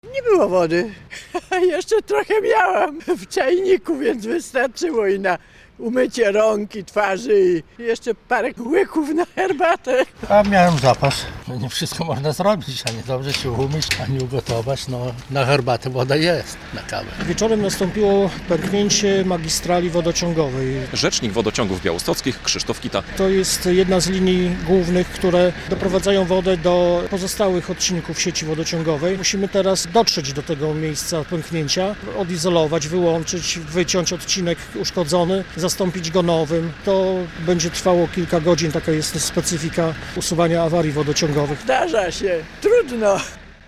Awaria sieci wodociągowej w Białymstoku - relacja